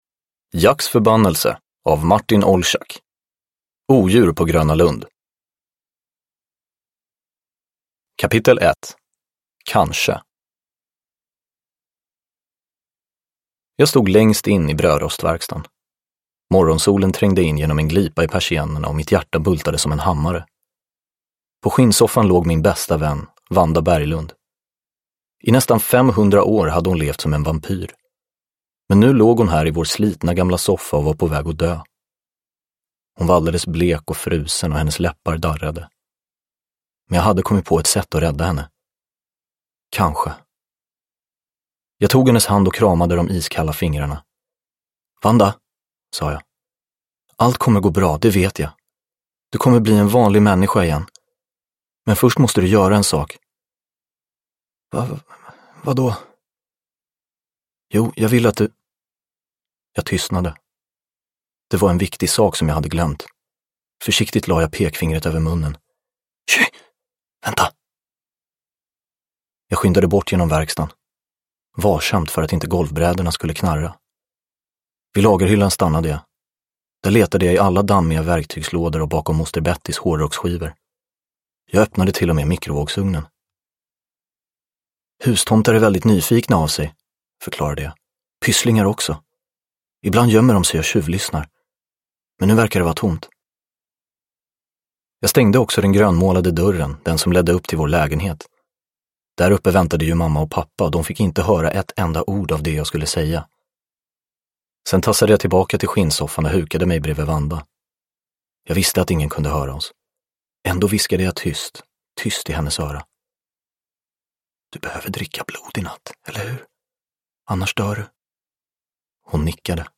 Odjur på Gröna Lund – Ljudbok